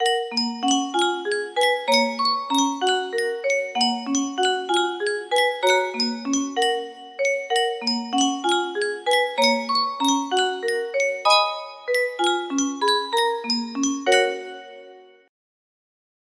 Yunsheng Music Box - Pop Goes the Weasel Y239 music box melody
Full range 60